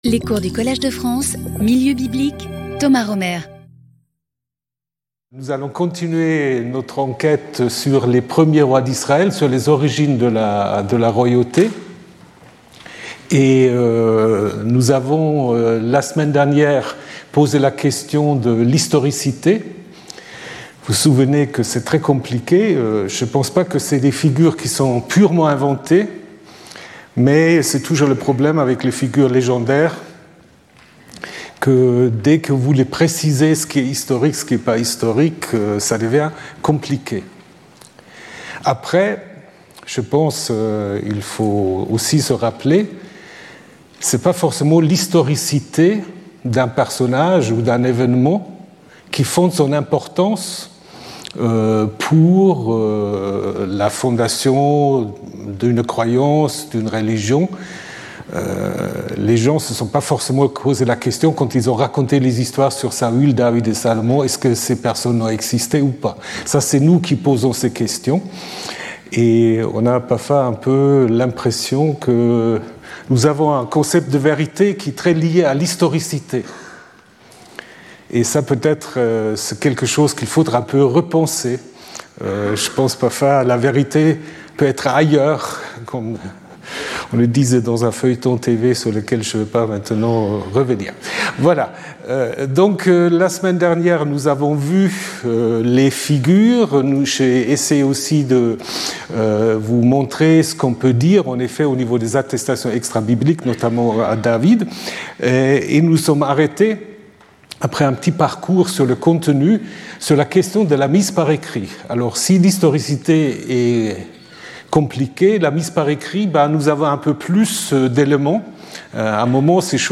Skip youtube video player Listen to audio Download audio Audio recording Documents and media Download support pdf (3.66 MB) Abstract The 1 st book of Samuel contains various accounts of the origins of kingship, in which the prophet Samuel and Saul play an important role. Some of these stories present kingship in a positive light, while others criticize it. The aim is to understand this ambiguity. Speaker(s) Thomas Römer Professor and Administrator of the Collège de France Events Previous Lecture 12 Feb 2026 14:00 to 15:00 Thomas Römer Saul, David, Solomon: mythical or historical figures?